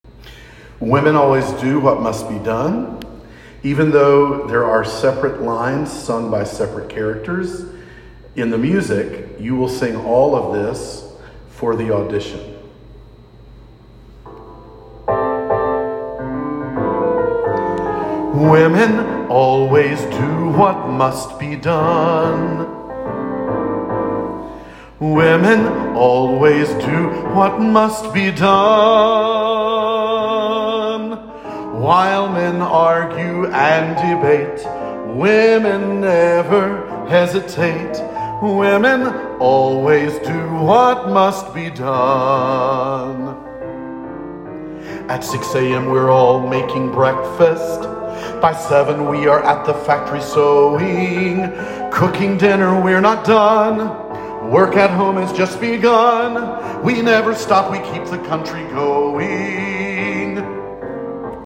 Women Always Do What Must Be Done – Vocal DemoDownload